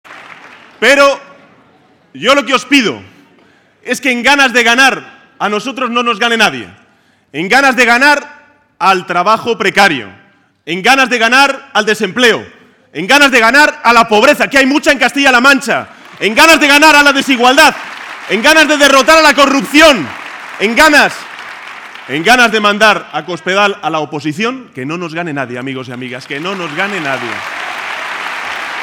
Audio Sánchez-acto Albacete